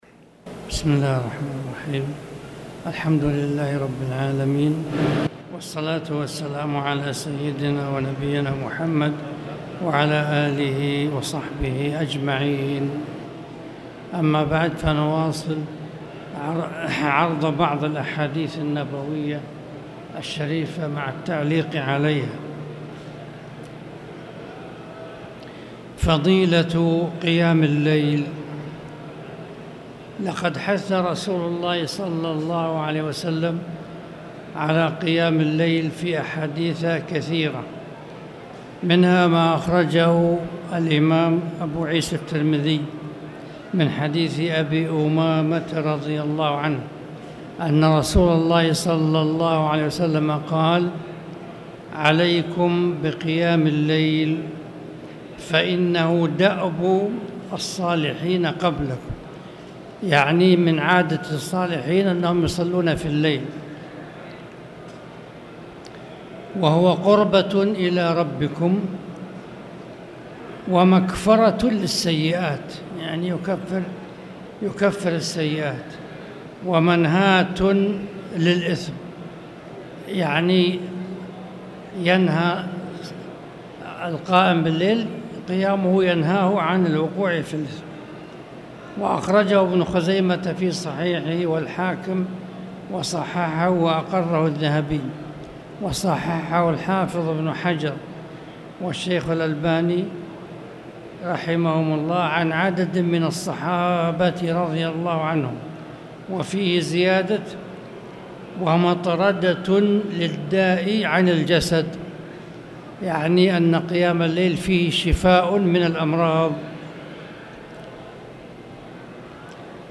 تاريخ النشر ٢١ محرم ١٤٣٨ هـ المكان: المسجد الحرام الشيخ